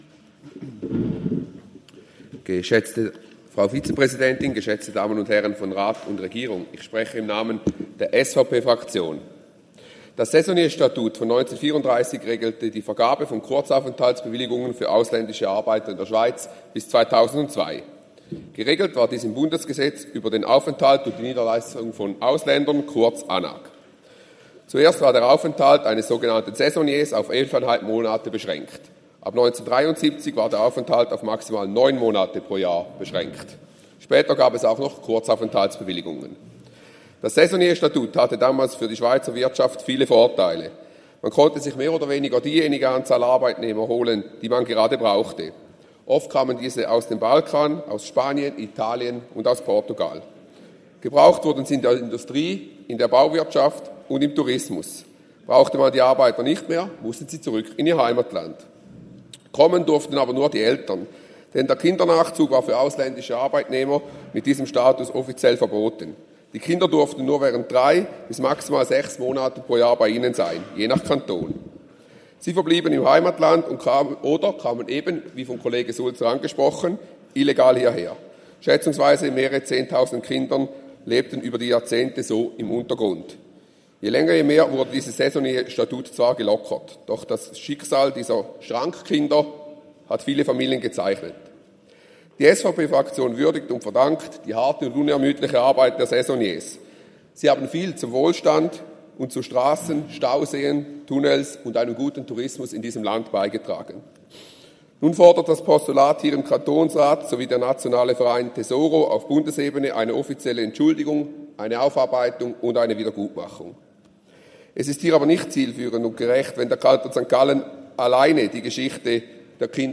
Session des Kantonsrates vom 29. April bis 2. Mai 2024, Aufräumsession
1.5.2024Wortmeldung